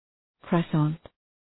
Shkrimi fonetik {krwɑ:’sɒn}